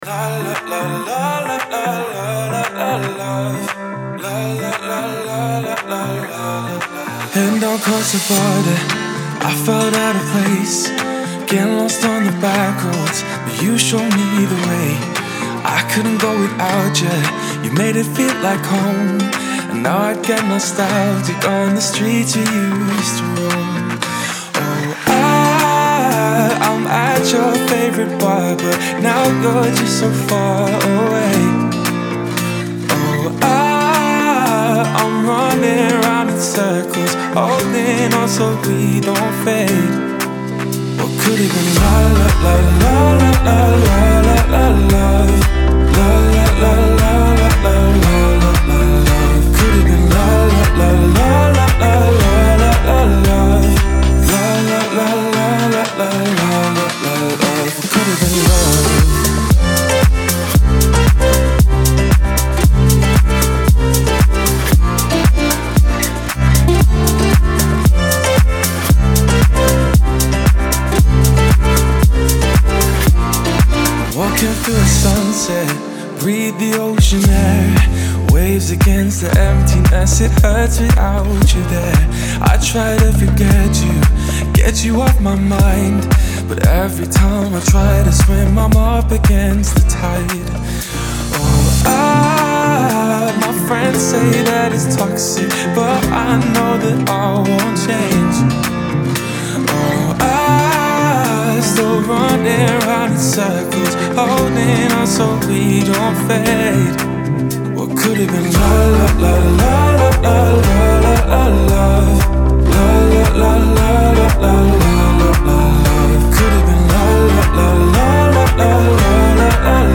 Лирика , ХАУС-РЭП